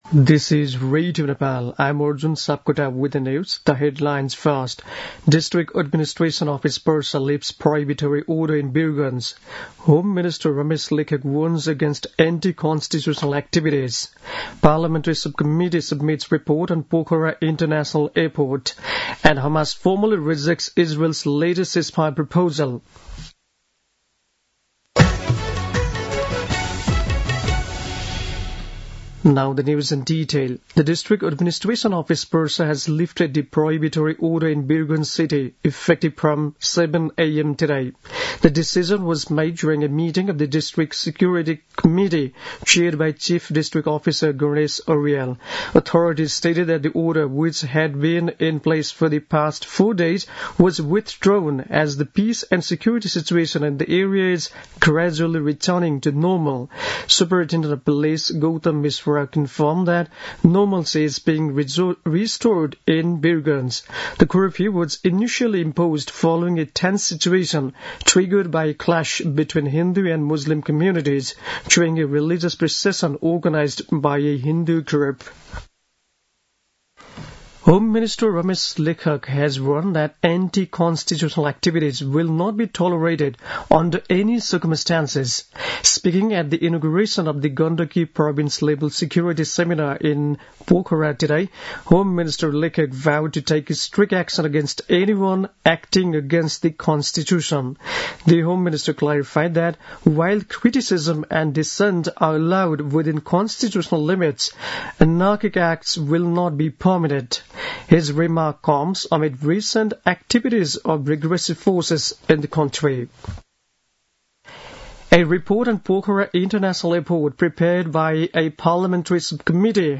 दिउँसो २ बजेको अङ्ग्रेजी समाचार : ५ वैशाख , २०८२